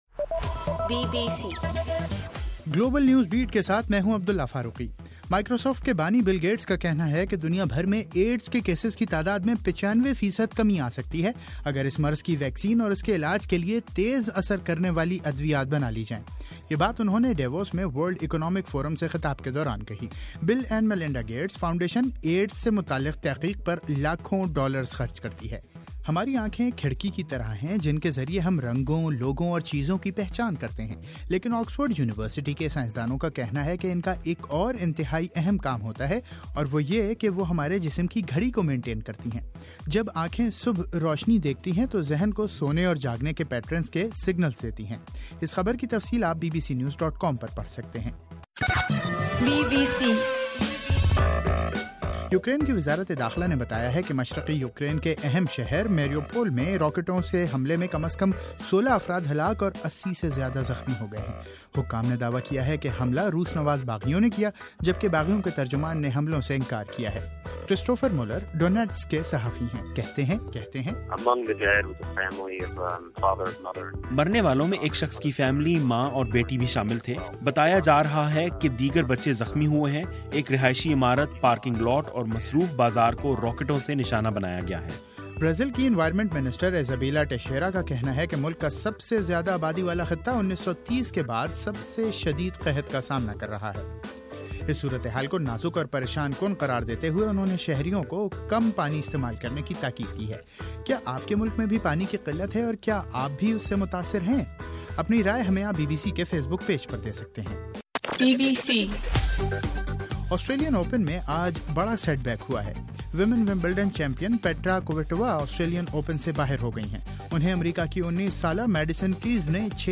جنوری 24: رات 9 بجے کا گلوبل نیوز بیٹ بُلیٹن